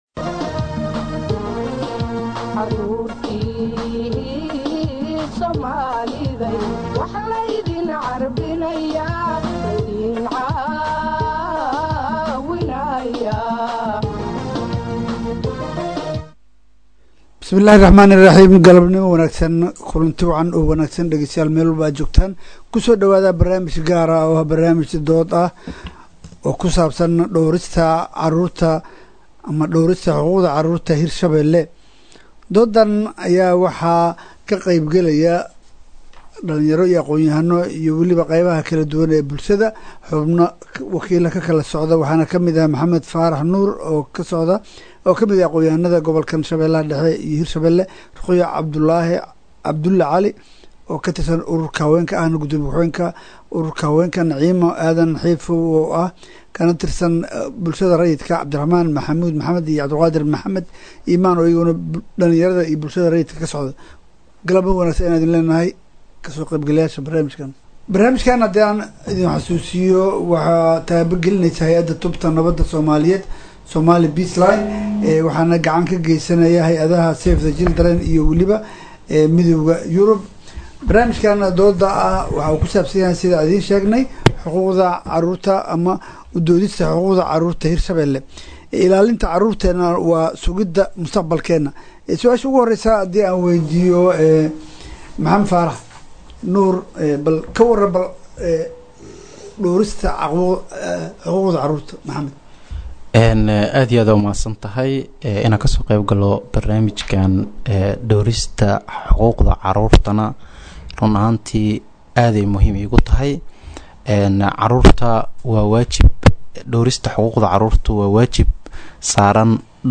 Dhagsyso Barnasmij Dood-Wadaag ah oo ku saabsan Dhowrista Xuquuqda Carruurta Doodan waxaa Taabagelisay Somali Peace Line.